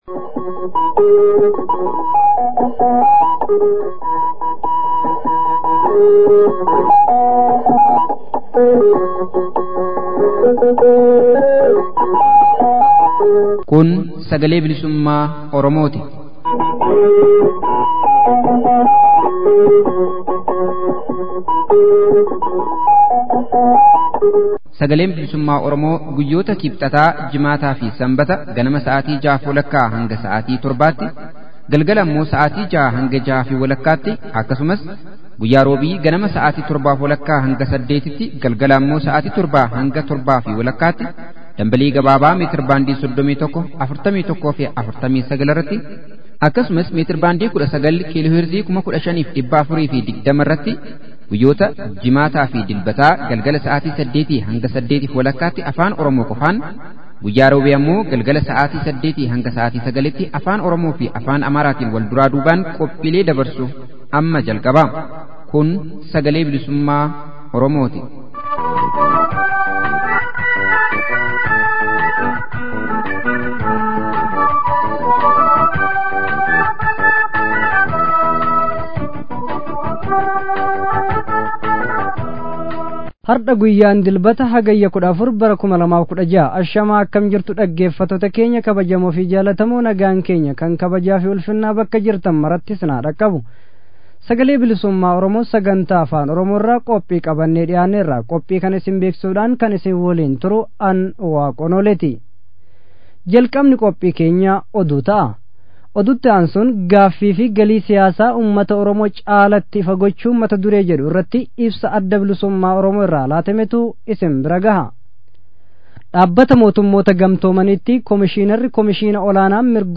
SBO: Hagayya 14 Bara 2016. Oduu, Ibsa ABO, Gabaasa sochii Warraaqsa FXG finiinaa jiruu fi Gaaffii fi deebii Ka’imman Oromoo gara mooraa QBOtti dhufan waliin taasifame -Kutaa xumuraa.